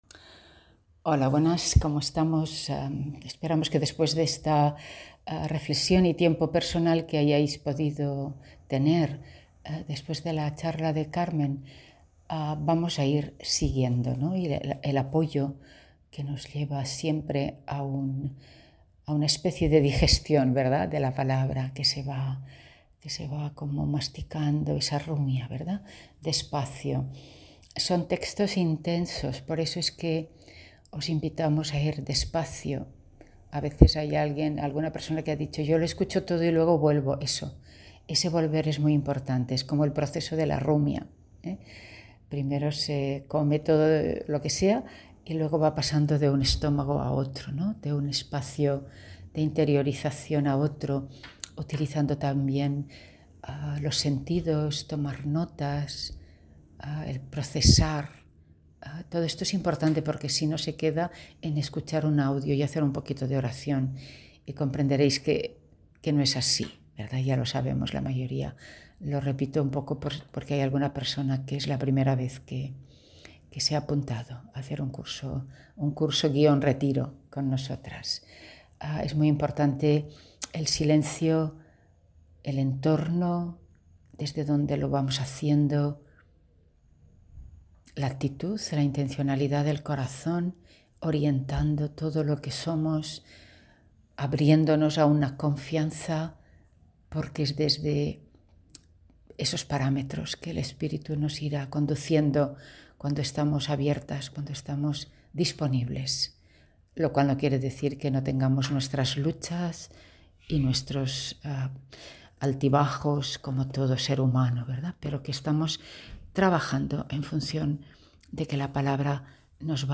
Este audio que os ofrecemos forma parte de un retiro que dimos con el evangelio de Juan.